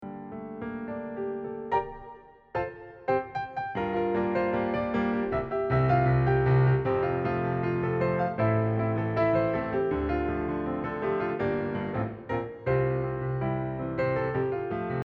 piano versions
reimagined with a more relaxed tone